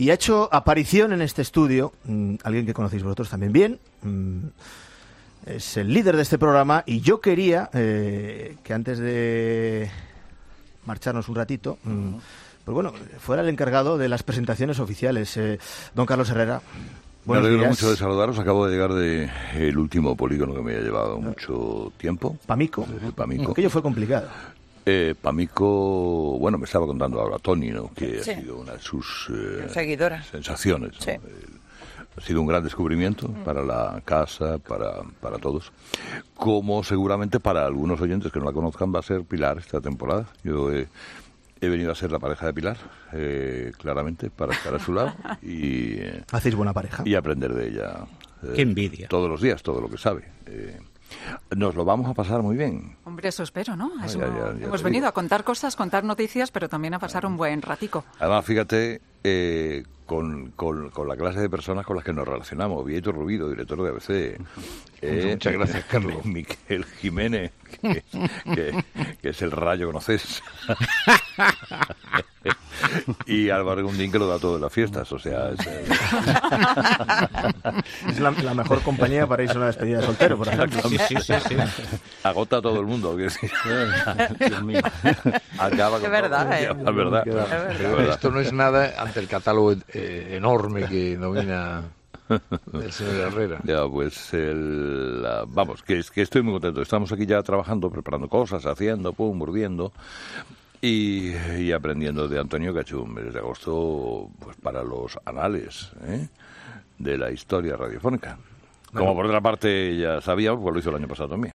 Carlos Herrera presenta en antena a Pilar García Muñiz: "He venido a ser su pareja"